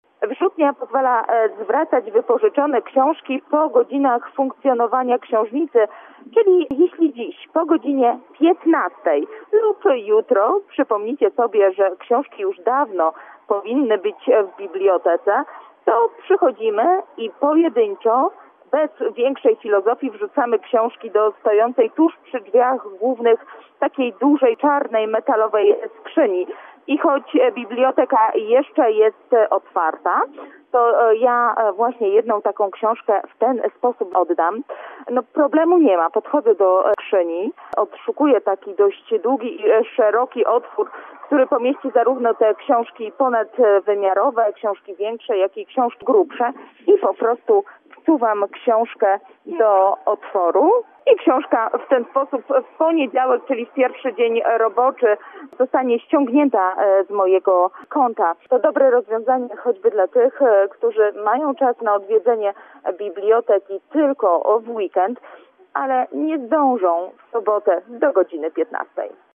Więcej w relacji naszej reporterki